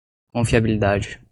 Pronunciat com a (IPA)
/kõ.fi.a.bi.liˈda.d͡ʒi/